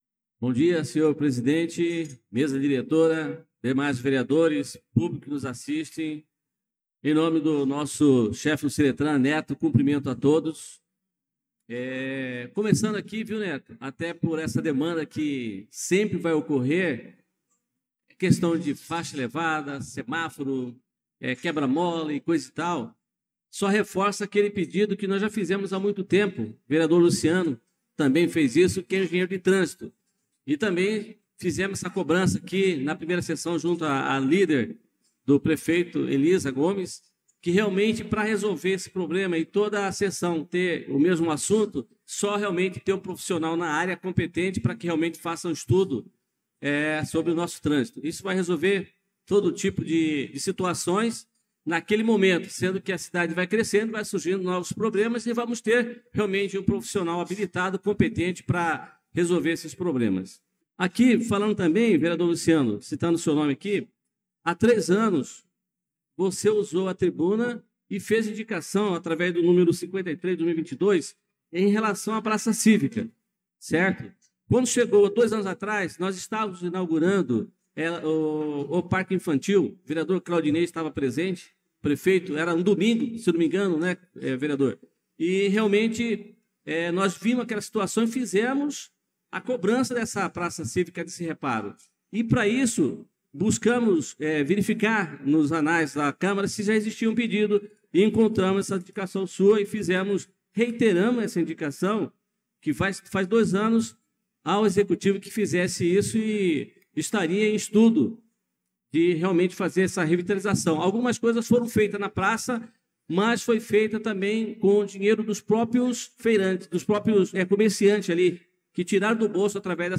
Pronunciamento do vereador Adelson Servidor na Sessão Ordinária do dia 11/02/2025